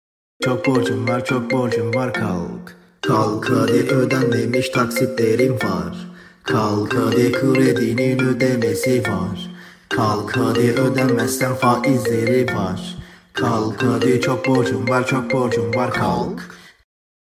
Bu popüler alarm sesi ile güne enerjik bir başlangıç yapın!